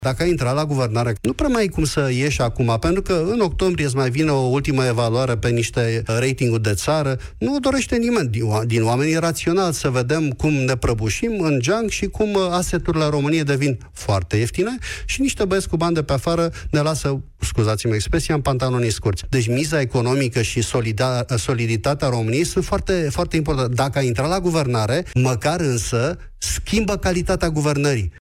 Prezent la Piața Victoriei de la Europa FM, Titus Corlățean acuză actuala conducere a social democraților de rezultatele dezastruoase de la ultimele alegeri și spune că deciziile în partid sunt luate în continuare de aceeași echipă: Marcel Ciolacu, fost șef PSD, și Sorin Grindeanu, actualul președinte interimar PSD.